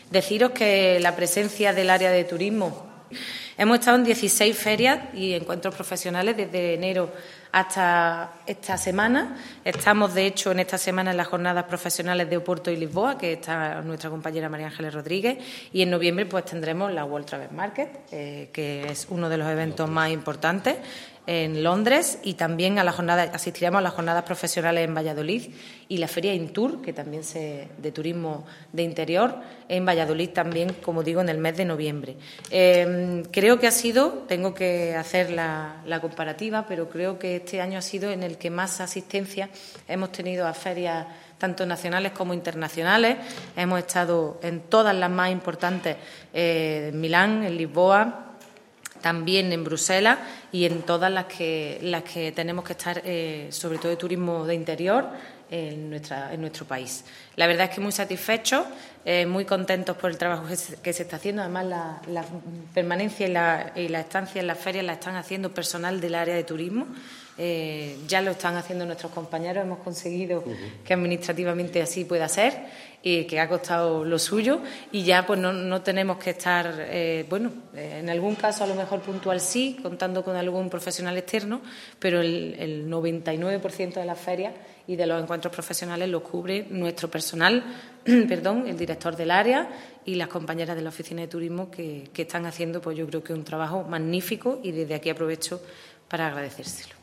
El alcalde de Antequera, Manolo Barón, y la teniente de alcalde delegada de Turismo, Ana Cebrián, han informado hoy en rueda de prensa sobre los datos más relevantes que ha dado de sí el turismo en nuestra ciudad entre los meses de enero y septiembre del presente año 2022.
Cortes de voz